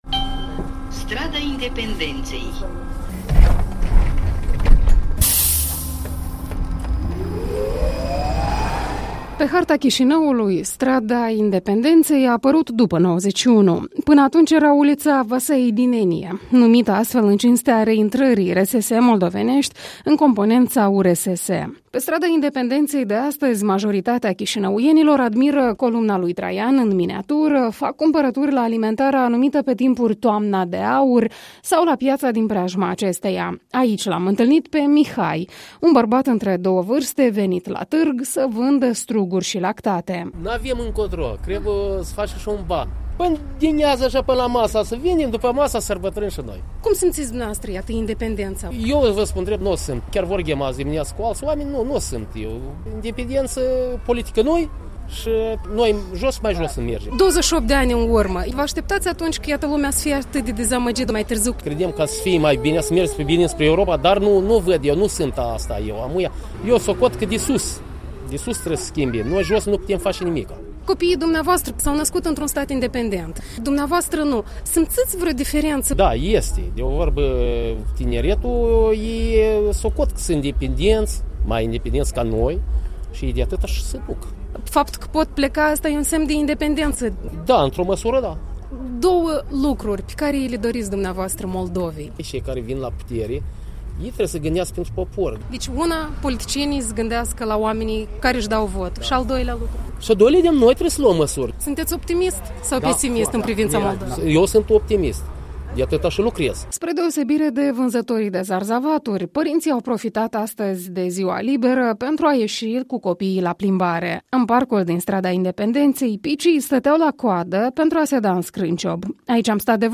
Corupția, mentalitatea învechită și delăsarea ar fi câteva câteva motive ce au frânat dezvoltarea țării desprinsă în vara lui 1991 din fosta URSS, sunt de părere orășenii întâlniți ocazional pe stradă.
Reportaj despre independența R. Moldova pe strada Independenței din Chișinău